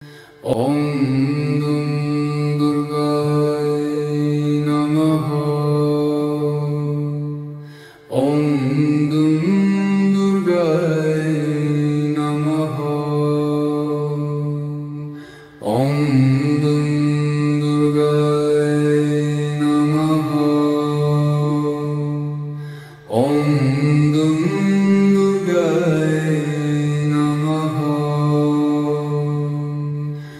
durga-mantra.mp3